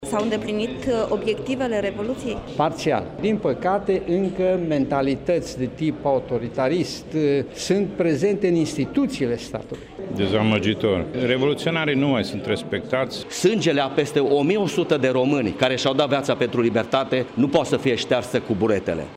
vox-tm.mp3